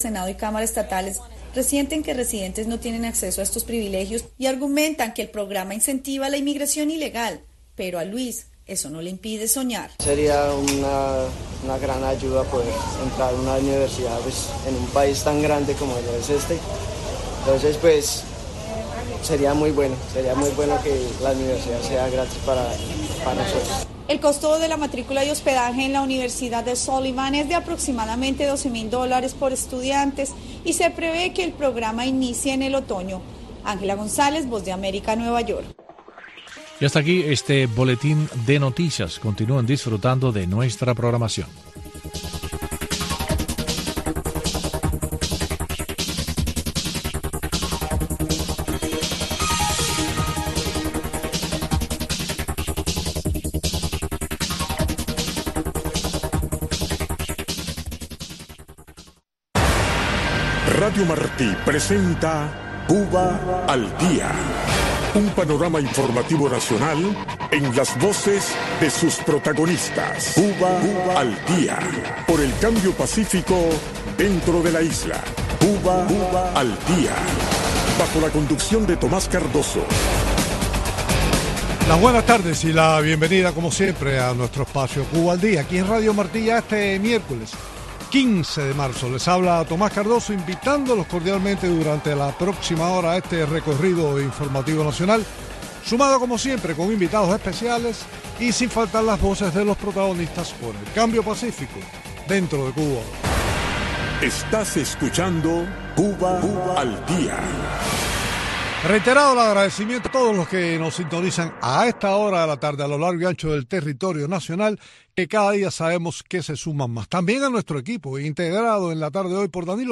espacio informativo en vivo